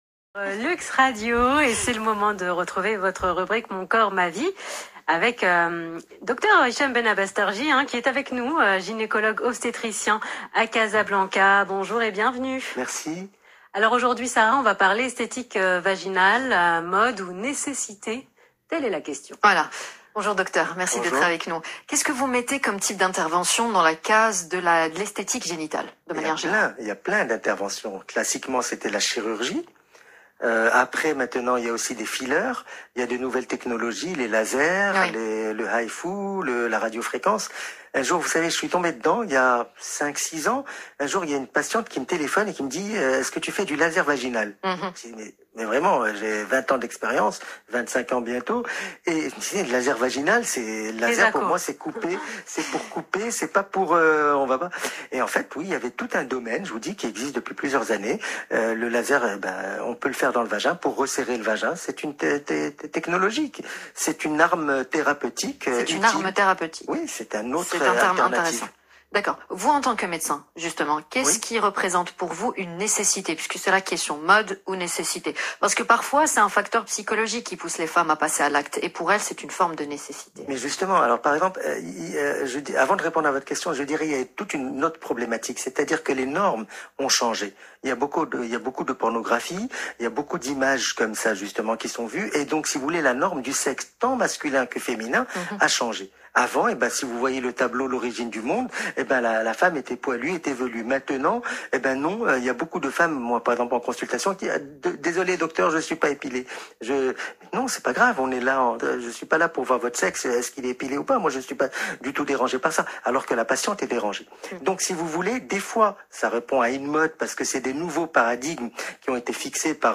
Plus d’explications dans cette interview de l’Heure Essentielle sur Luxe Radio du 15 février 2022